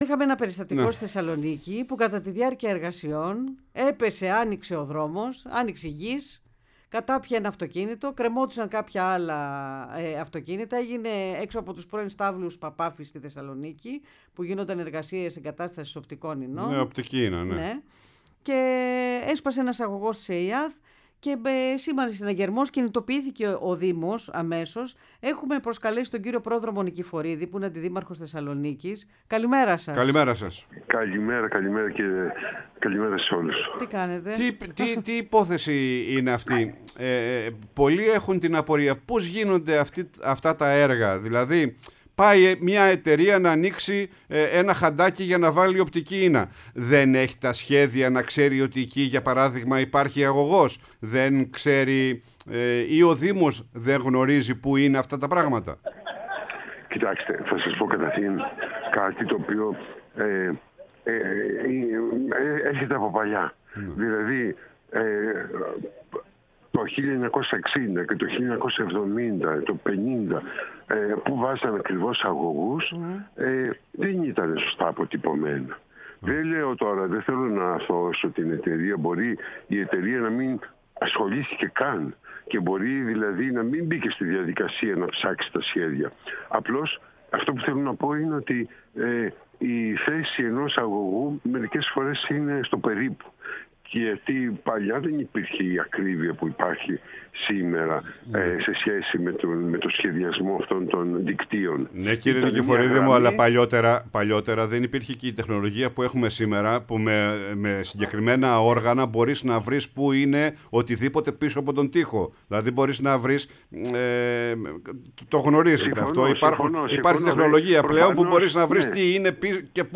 Ο Πρόδρομος Νικηφορίδης, Αντιδήμαρχος Θεσσαλονίκης, μίλησε στην εκπομπή «Πρωινή Παρέα»